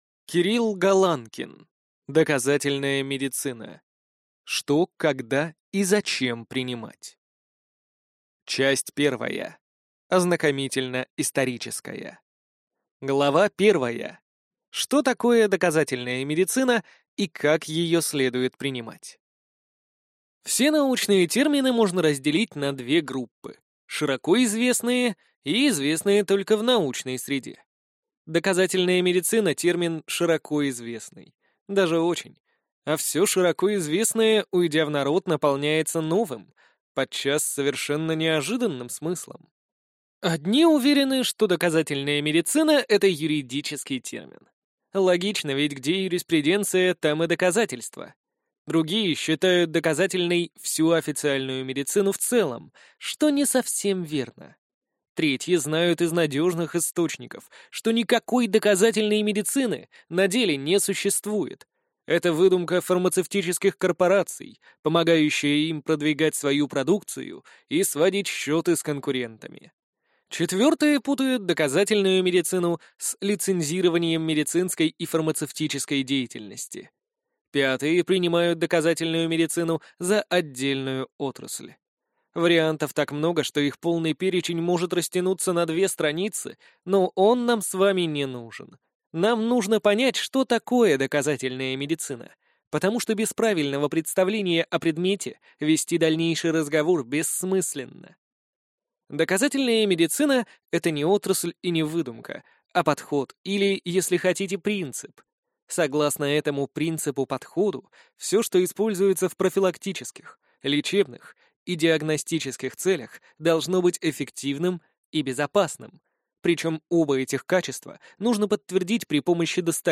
Аудиокнига Доказательная медицина. Что, когда и зачем принимать | Библиотека аудиокниг